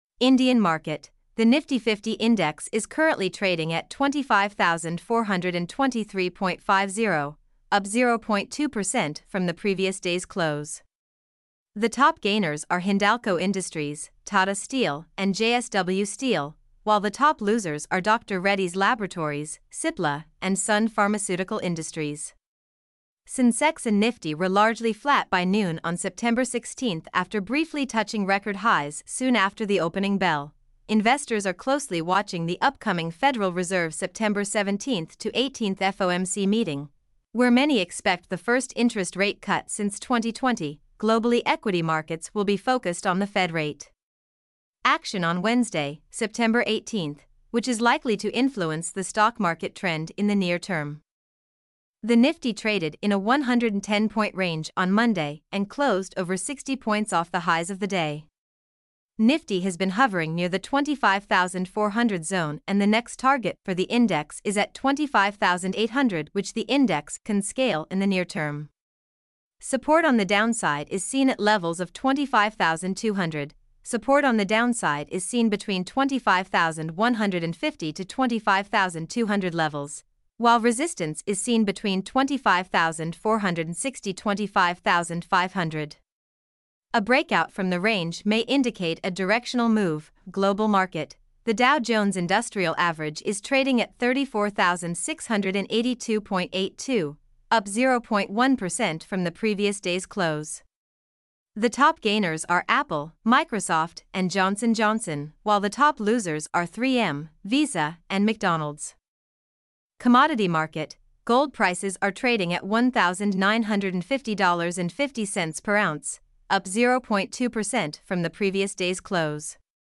mp3-output-ttsfreedotcom-2.mp3